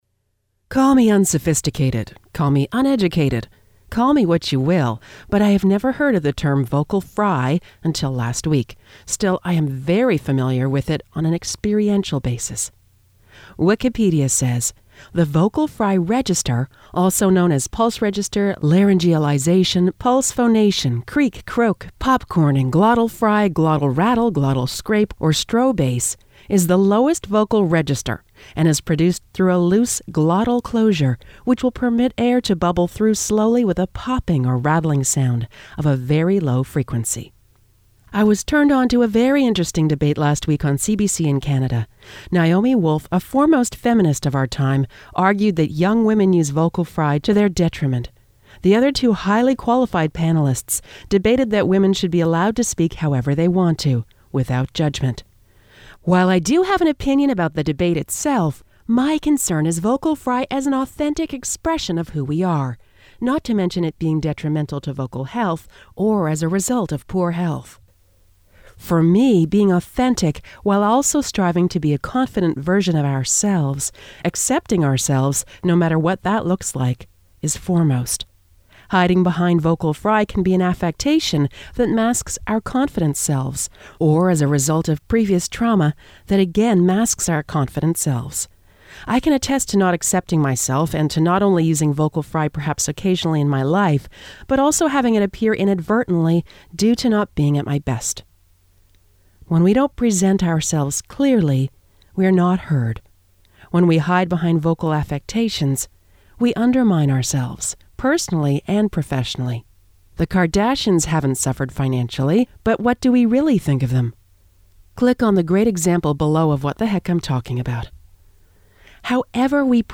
audio-blog-call-me-irresponsible.mp3